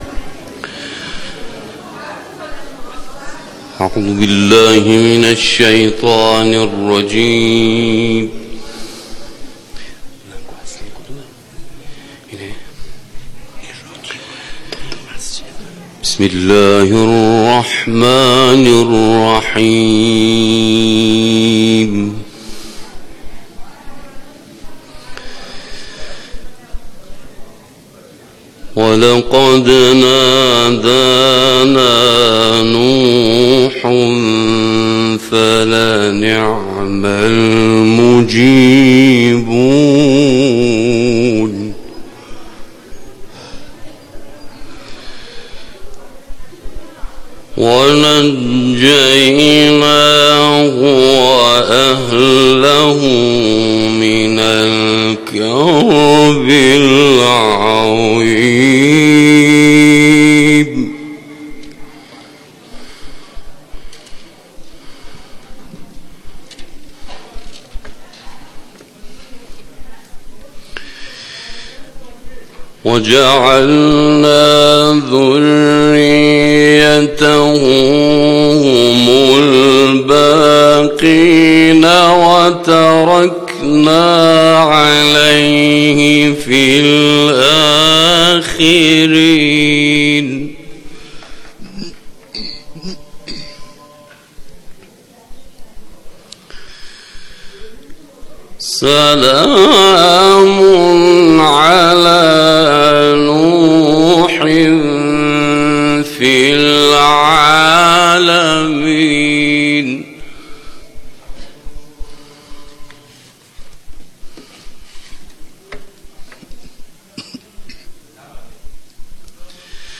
در مسجد جامع لویزان
تلاوت آیاتی از سوره مبارکه صافات